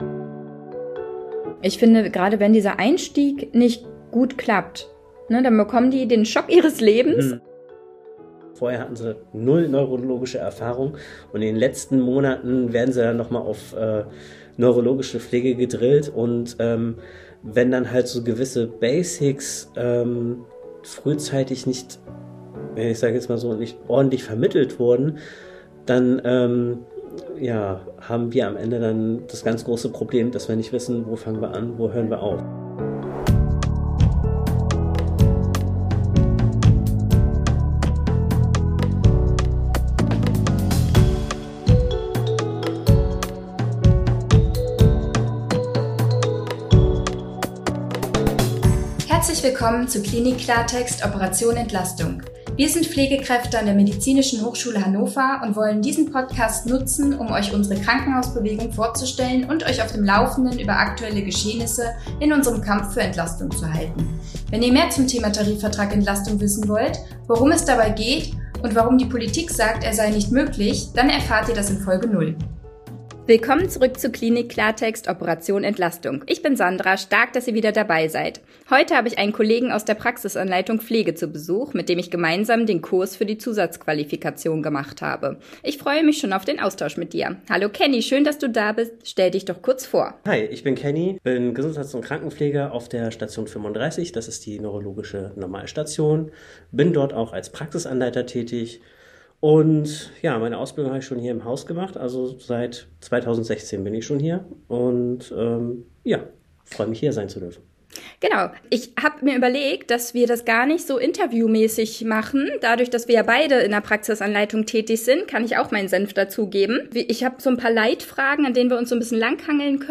Praxisanleitung Pflege – Ein Interview